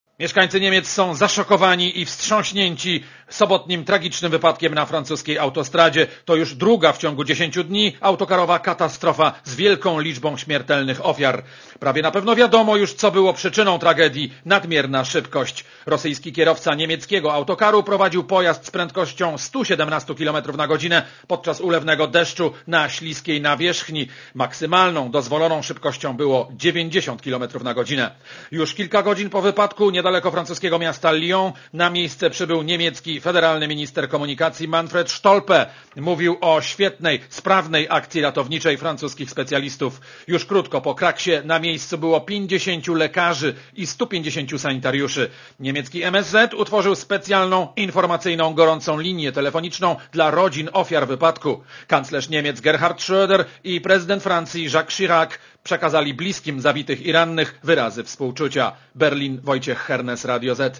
Posłuchaj relacji korepsondenta Radia Zet